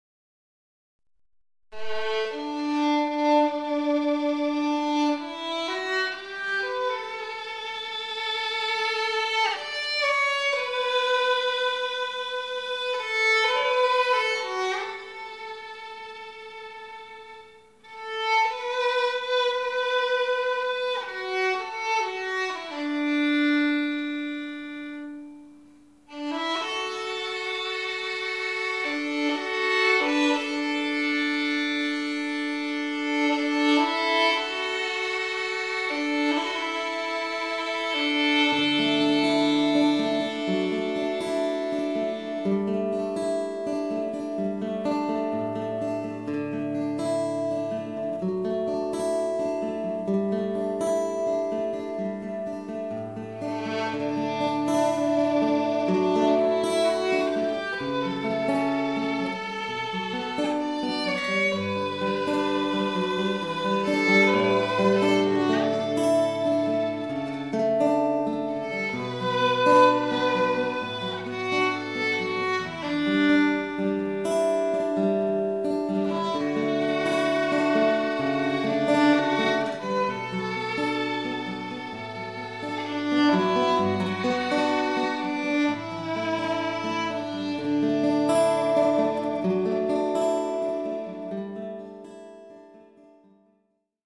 Oh Shenandoah American Folk Song Violin & Guitar Duo